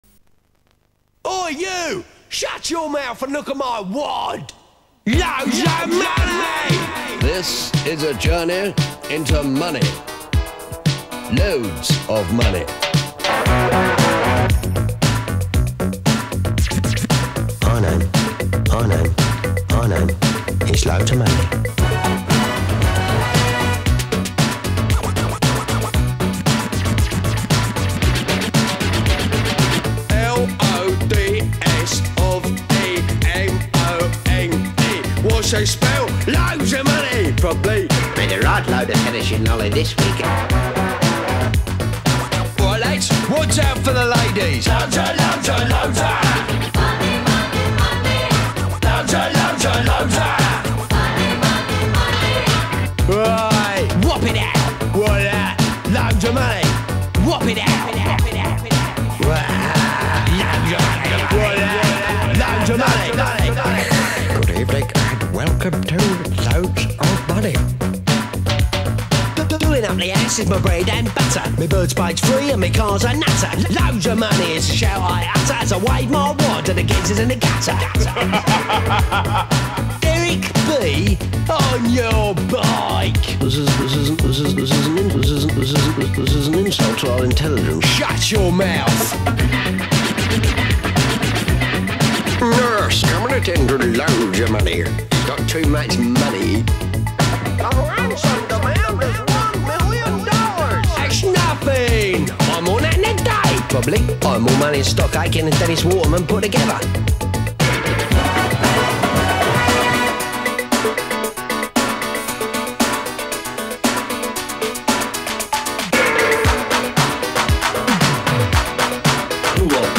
BPM115
Audio QualityCut From Video